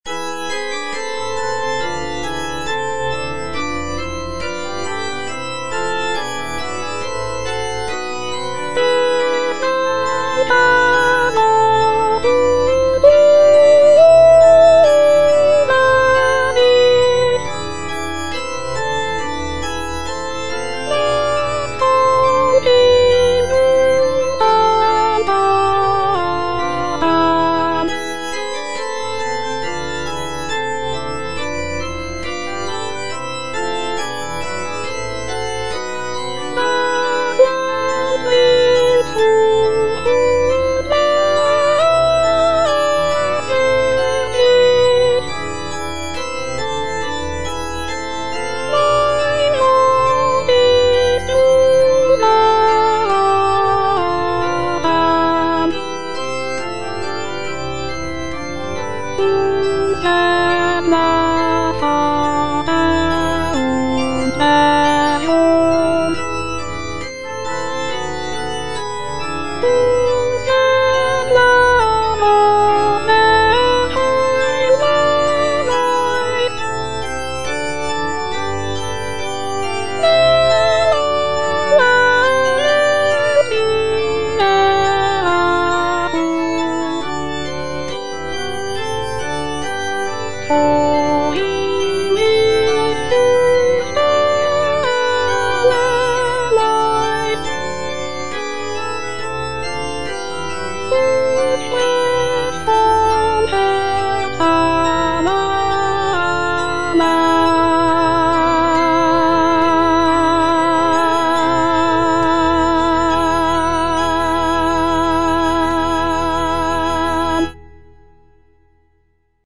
Cantata
Soprano (Voice with metronome) Ads stop